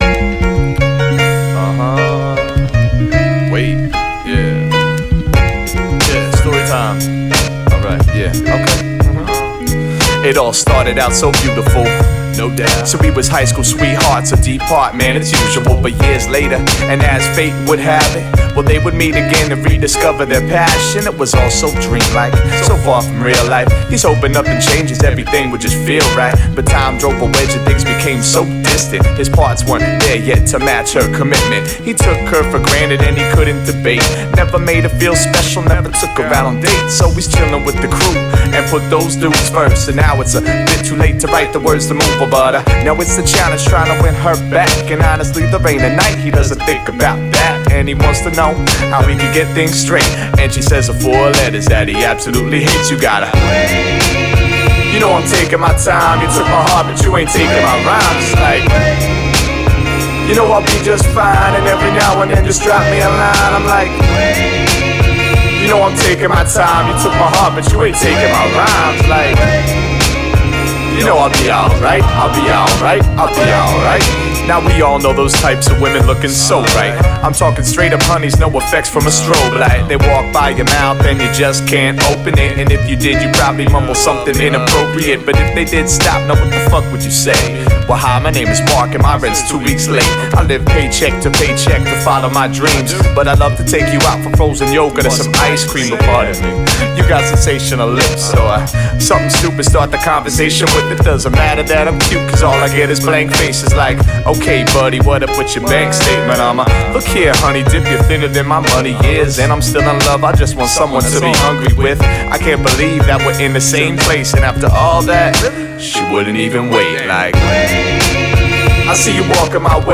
Hip-Hop